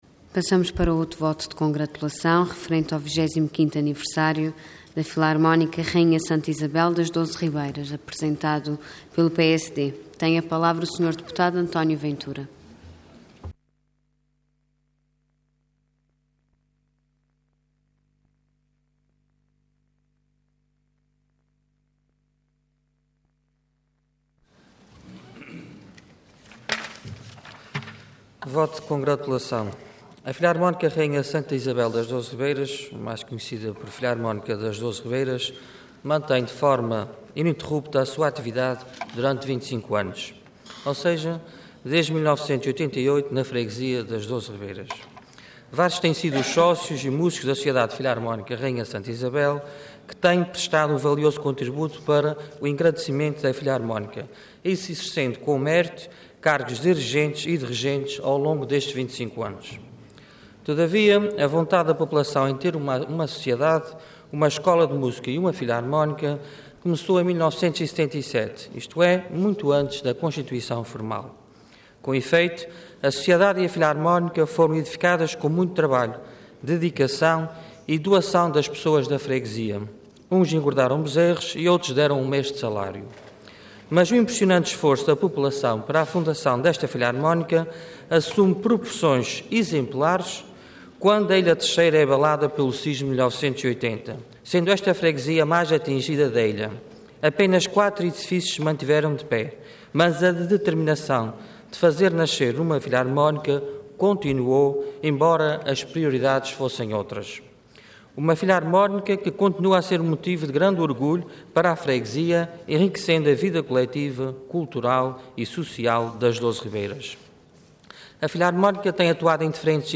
Intervenção Voto de Congratulação Orador Luís Rendeiro Cargo Deputado Entidade PSD